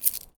R - Foley 24.wav